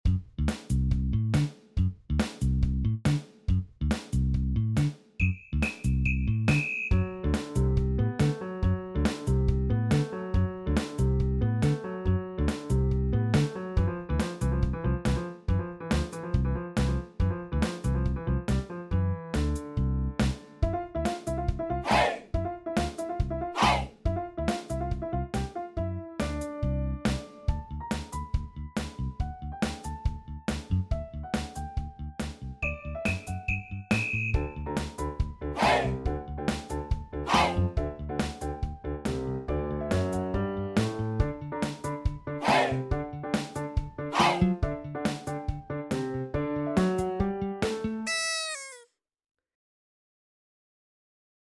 Made using LMMS